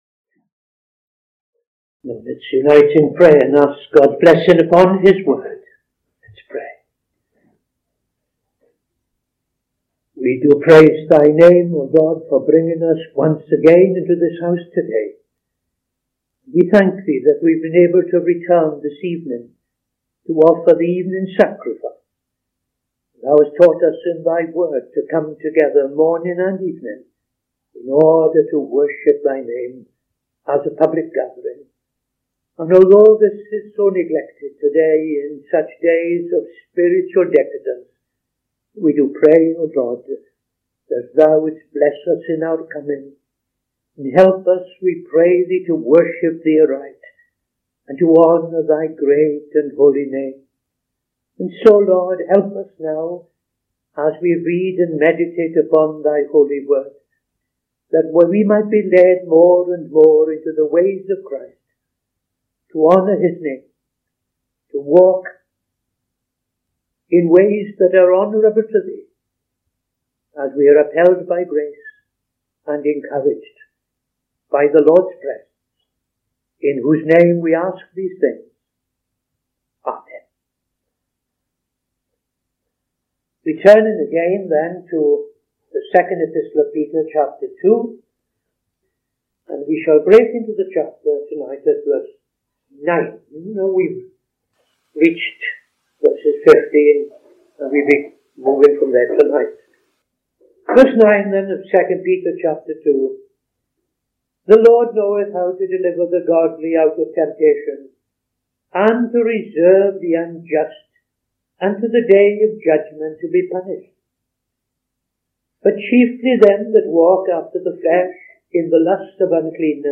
Opening Prayer and Reading II Peter 2:9-17